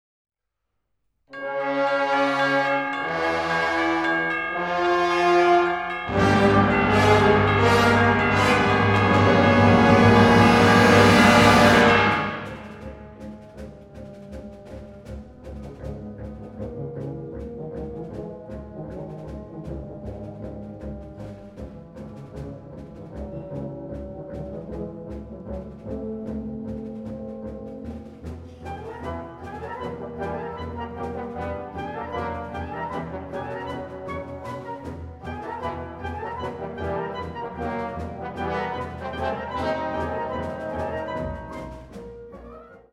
Besetzung Ha (Blasorchester)